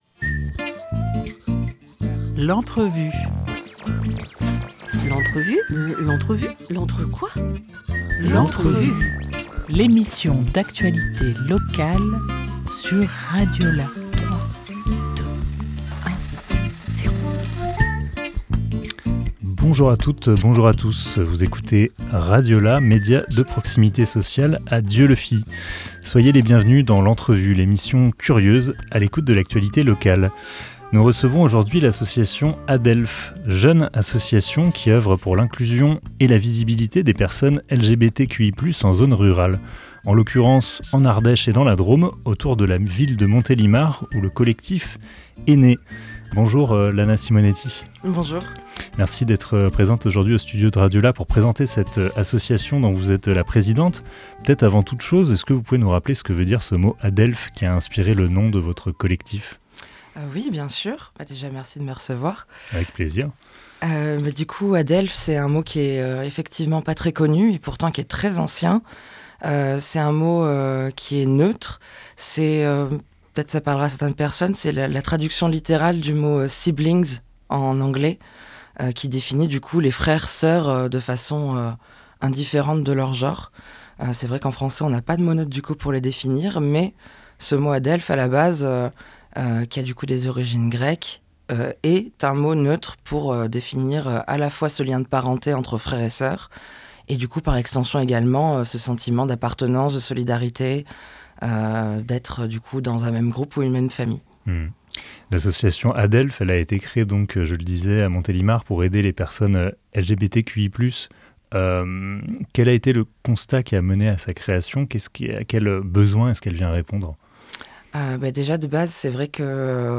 5 décembre 2024 11:14 | Interview